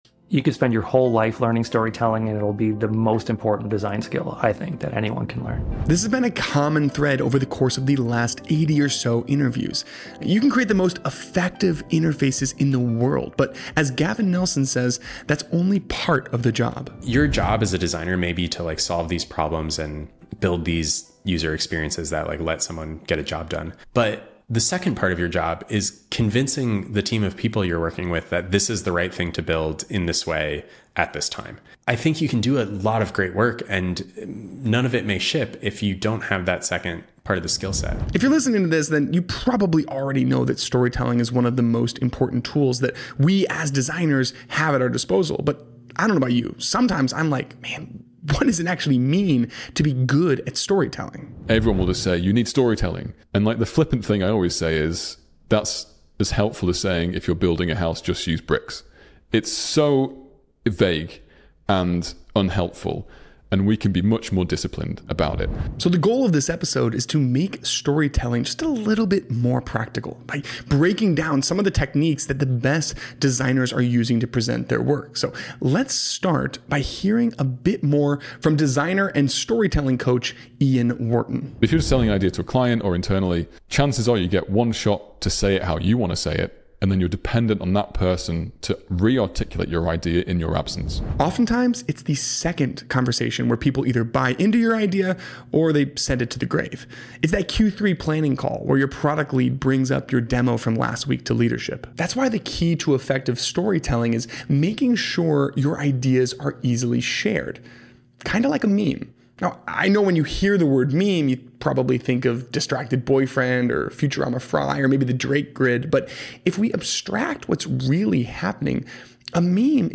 Dive Club is an interview series